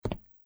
在较硬的路面上行走脚步单生－右声道－YS070525.mp3
通用动作/01人物/01移动状态/01硬地面/在较硬的路面上行走脚步单生－右声道－YS070525.mp3